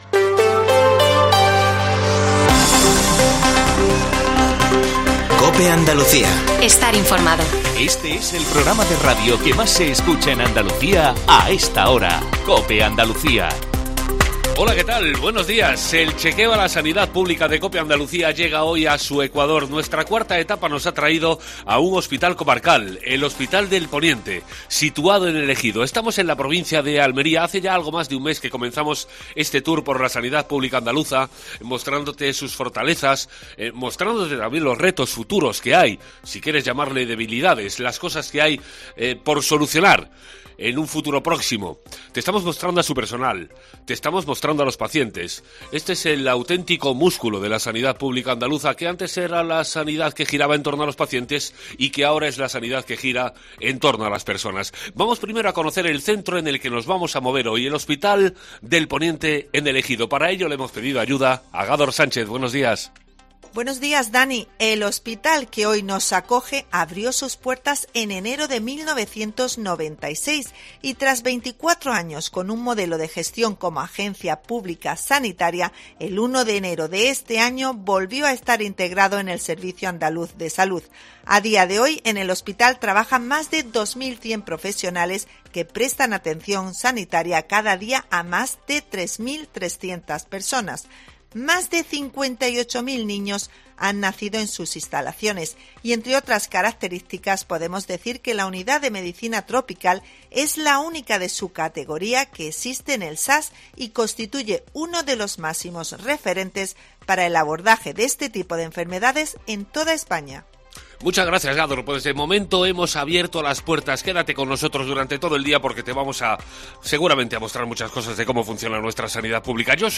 Herrera en COPE Andalucía 07.20 – 21 de abril - Desde el Hospital de Poniente en Almería